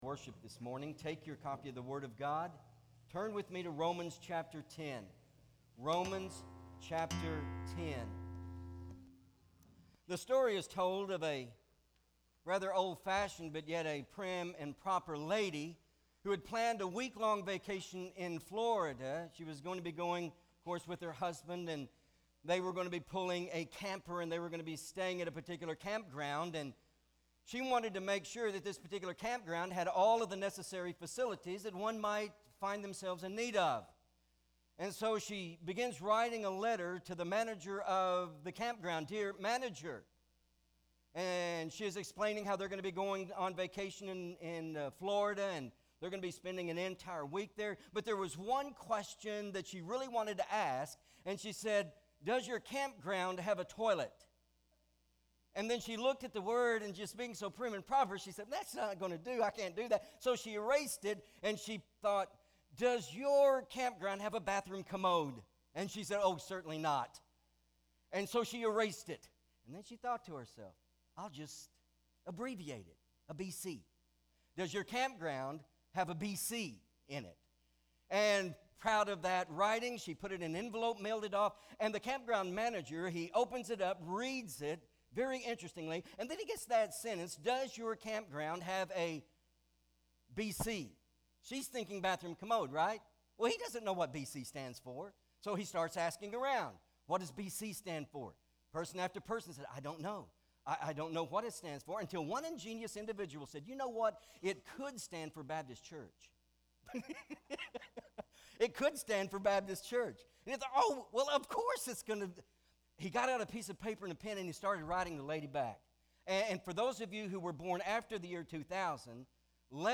Pt. 2 MP3 SUBSCRIBE on iTunes(Podcast) Notes Sermons in this Series Romans 10: 8-13 Not Ashamed!